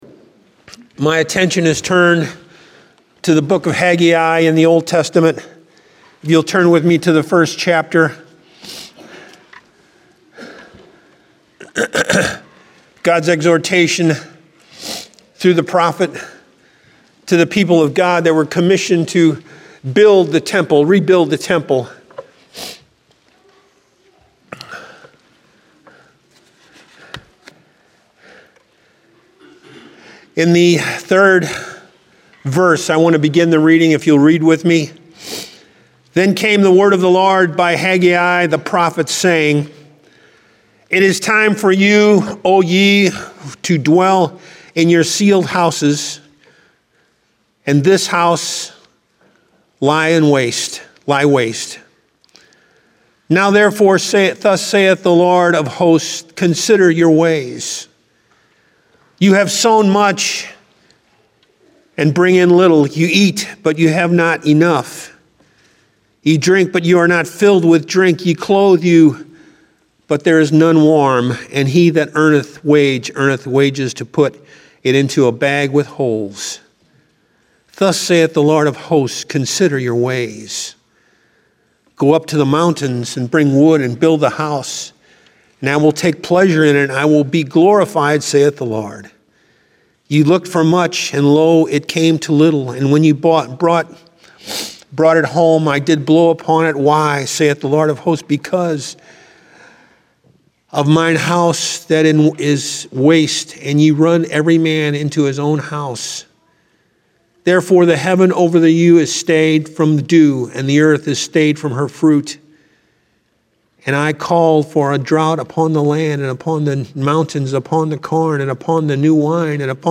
Download Sermon Audio File Evangelical Full Gospel Assembly